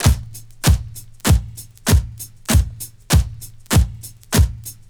SLOW AFRI.wav